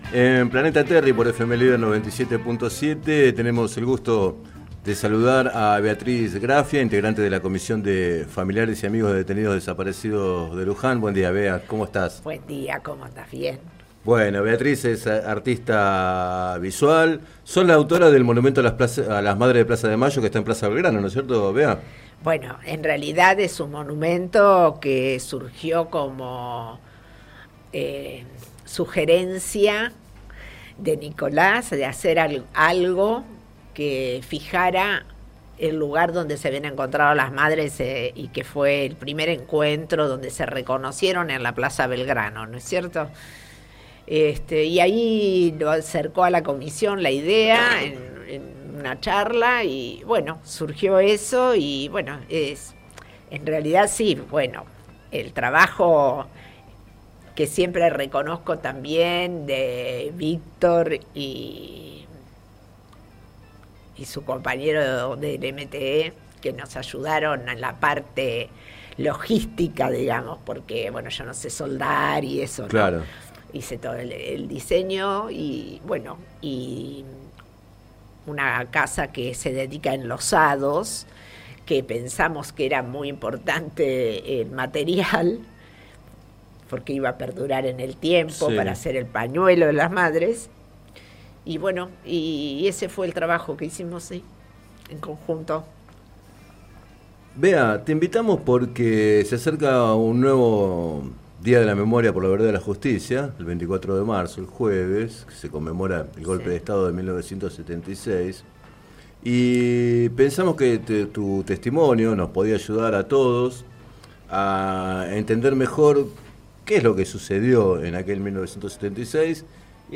Entrevistada en el programa Planeta Terri de FM Líder 97.7